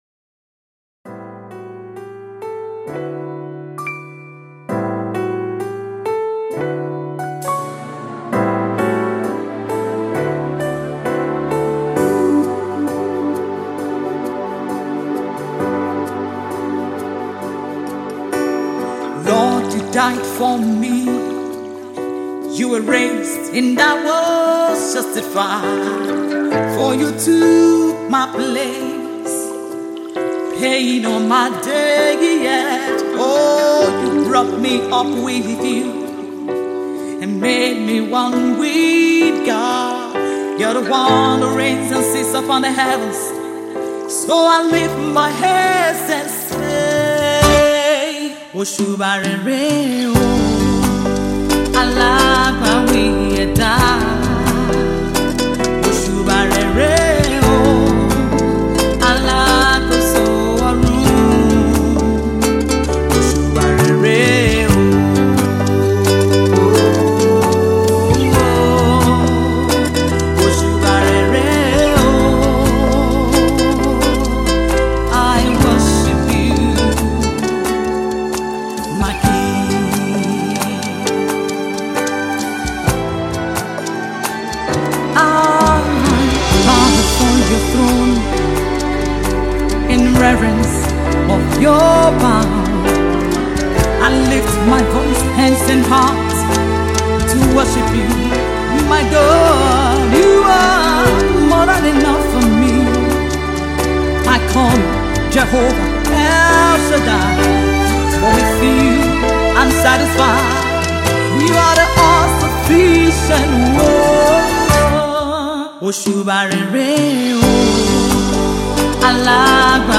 It’s a soft and gentle ballad with an orchestral feel.